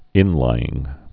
(ĭnlīĭng)